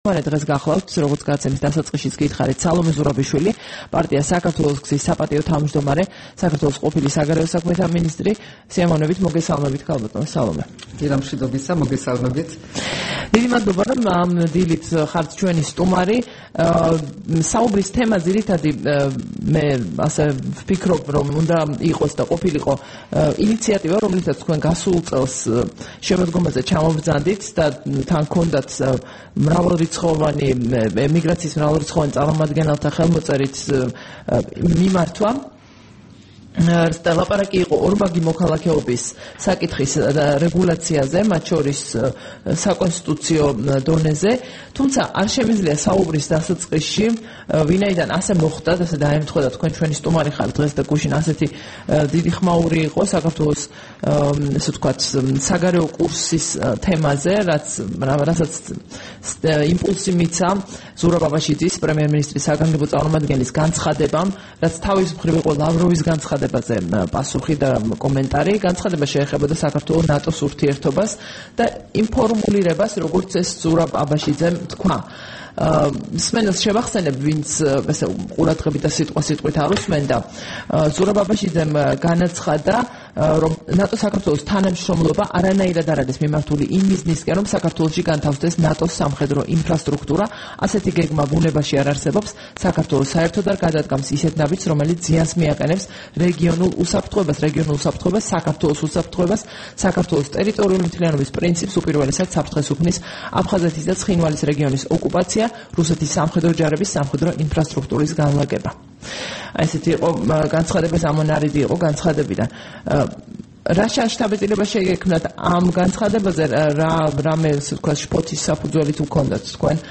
20 თებერვალს რადიო თავისუფლების დილის გადაცემის სტუმარი იყო სალომე ზურაბიშვილი, პარტია "საქართველოს გზის" საპატიო თავმჯდომარე, საგარეო საქმეთა ყოფილი მინისტრი.
საუბარი სალომე ზურაბიშვილთან